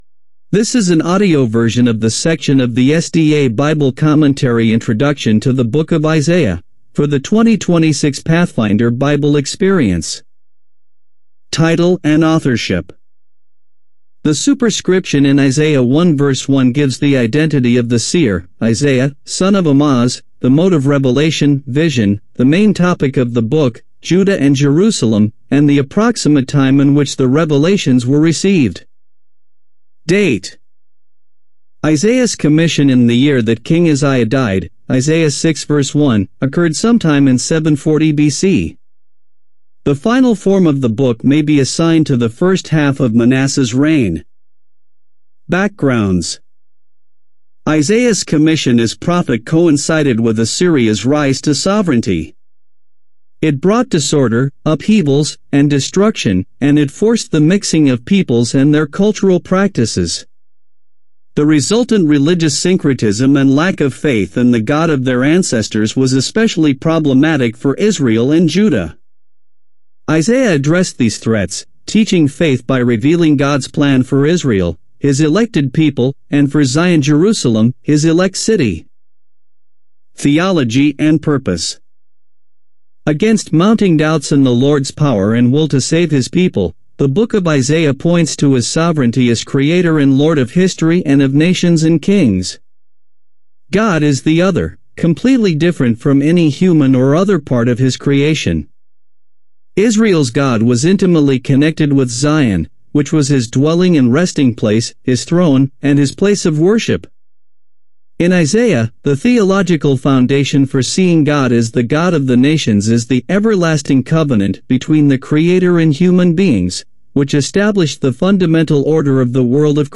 Click on the button below to listen to or download the audio version of the SDA Bible Commentary Intro to the Books of Isaiah.